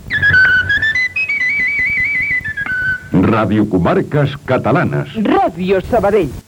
Indicatiu com Ràdio Comarques Catalanes.